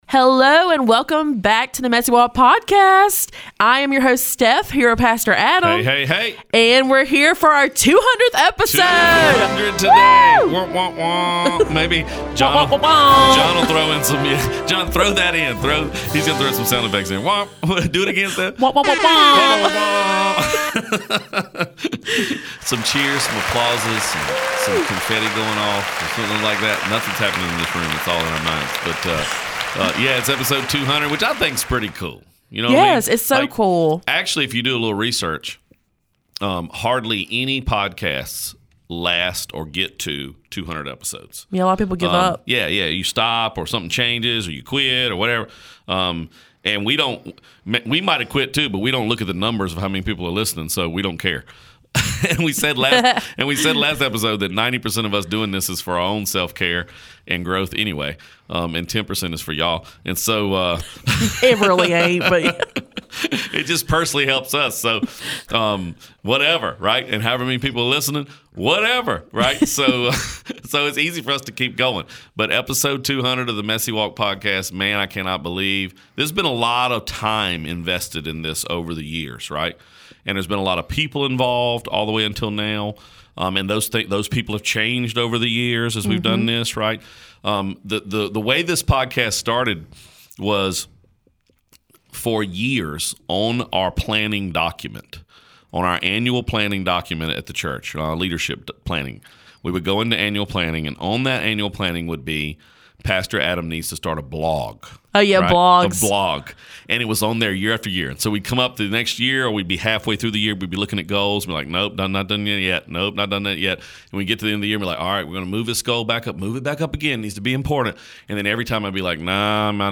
200: Special Episode - Blooper Reel